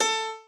b_piano1_v100l4o5a.ogg